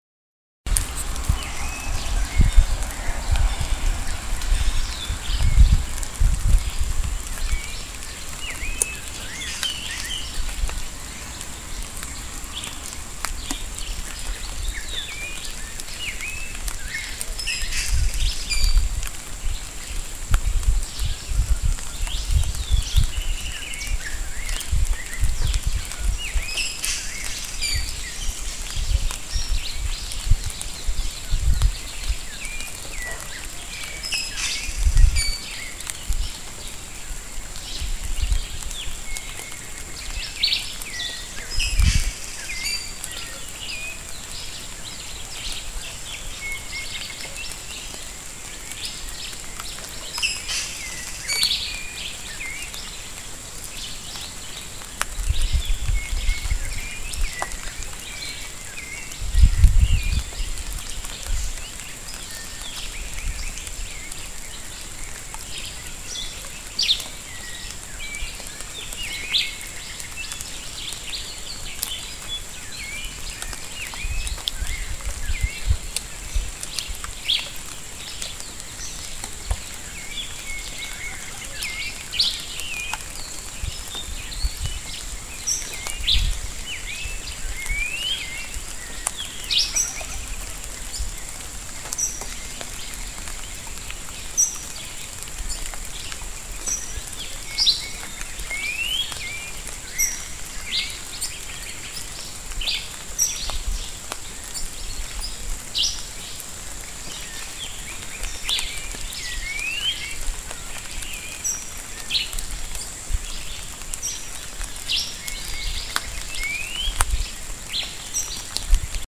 Reserva de la Biósfera, Sierra Manantlán. Colima.
Una mañana lluviosa en la Reserva de la Biósfera, sierra de Manantlán, región montañosa que cubre algunos municipios del Estado de Colima y Jalisco en Mexico.
Estamos a 18 grados celsius rodeados de una espesa vegetación y olor a lluvia, tierra mojada.
2013 Equipo: Tascam dr40